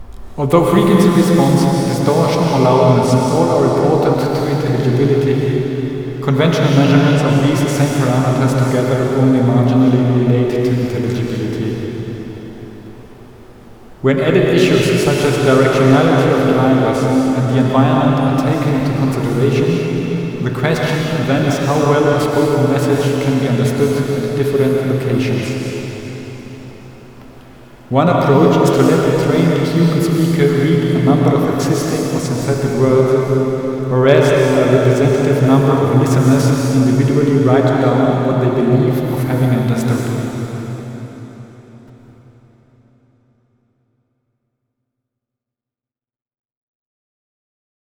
下面两段声音也直观展示了混响时间对清晰度的影响：
混响时间较大时.wav